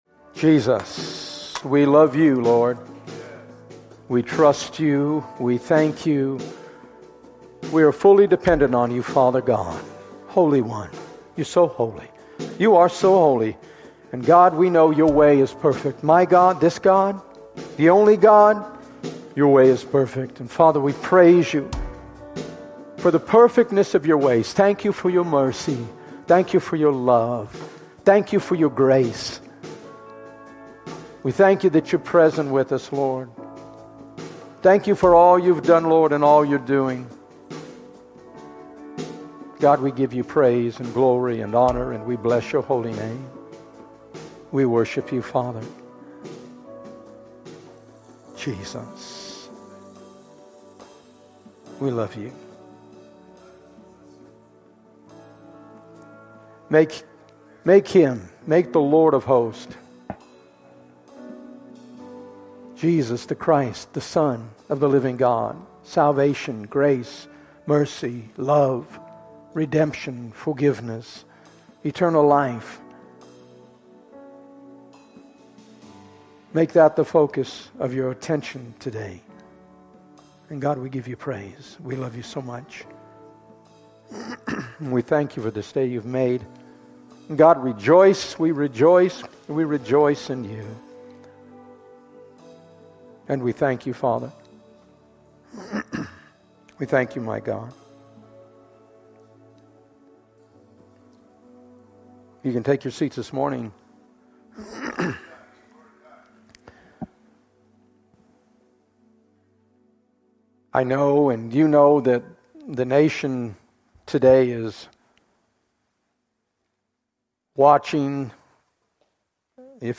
Sermon: Full Service: